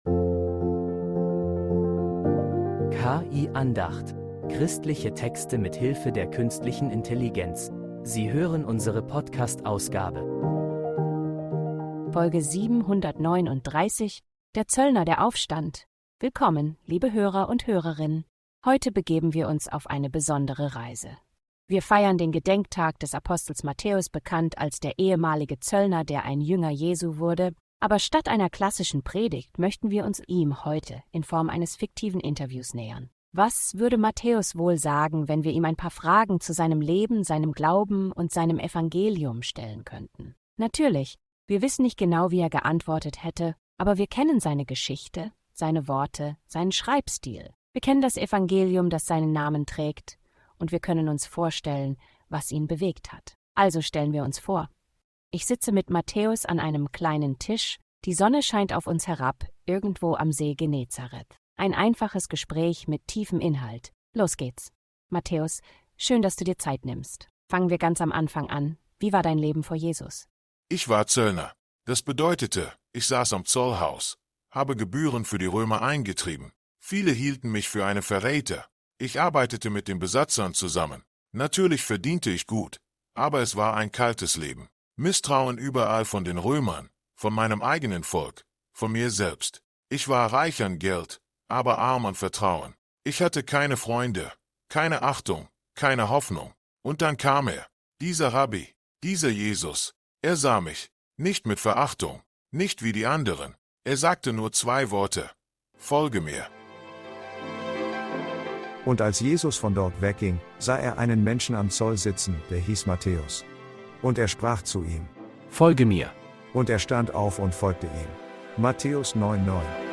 Ein fiktives Interview bringt uns Matthäus ganz nah – und seine